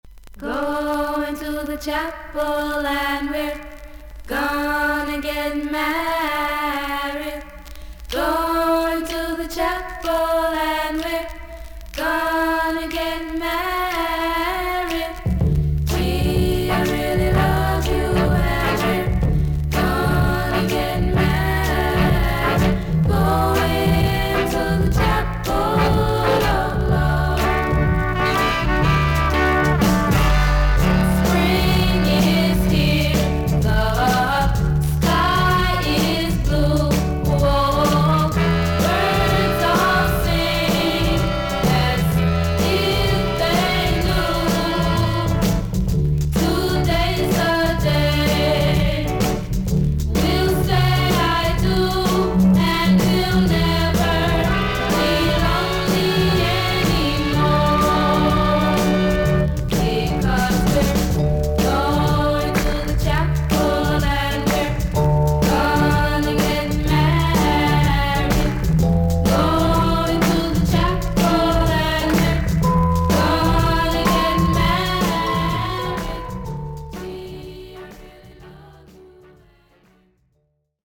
少々軽いパチノイズの箇所あり。少々サーフィス・ノイズあり。クリアな音です。
ガール・グループ。たどたどしい歌い方がかわいらし